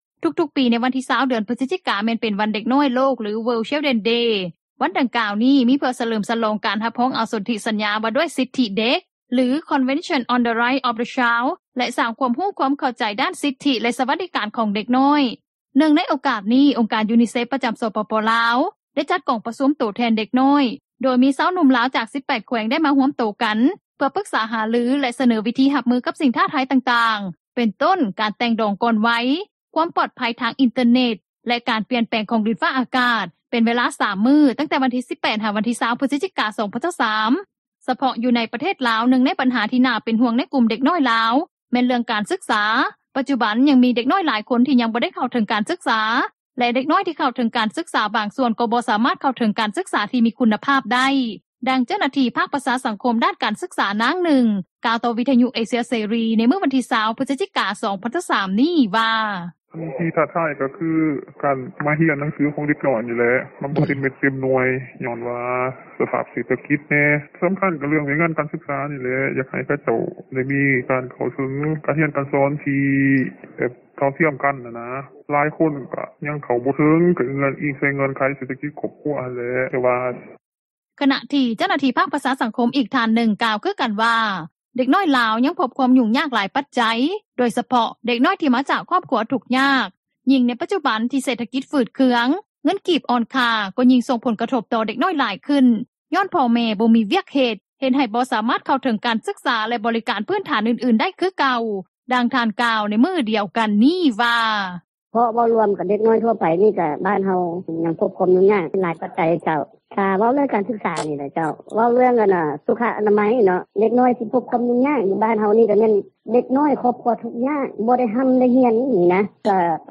ດັ່ງເຈົ້າໜ້າທີ່ພາກປະຊາສັງຄົມ ດ້ານການສຶກສາ ນາງນຶ່ງ ກ່າວຕໍ່ວິທຍຸເອເຊັຽເສຣີ ໃນວັນທີ 20 ພຶສຈິກາ 2023 ນີ້ວ່າ:
ດັ່ງຄຣູປະຖົມ ຢູ່ເມືອງຕຸ້ມລານ ແຂວງສາລະວັນ ທ່ານນຶ່ງ ກ່າວວ່າ: